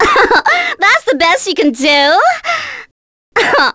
Viska-Vox_Victory.wav